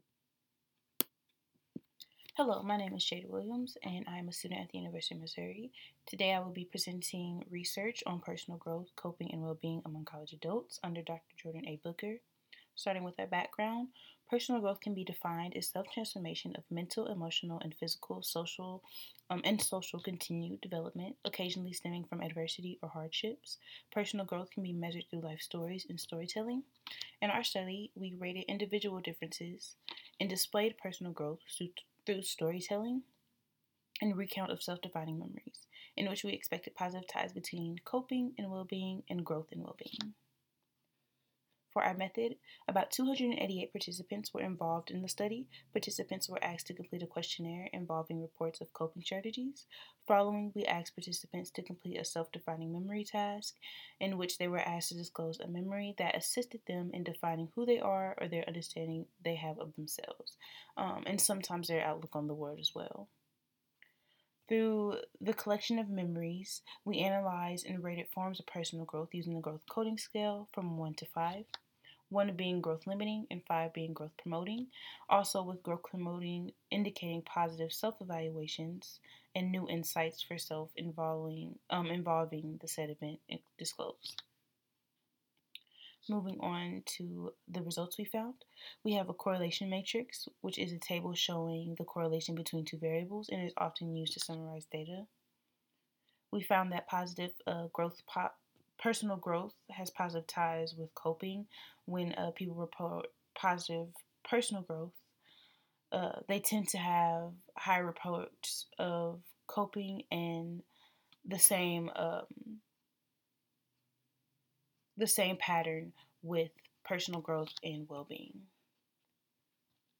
None: University of Missouri Undergraduate Research and Creative Achievements Forum (2020 : Columbia, Mo.)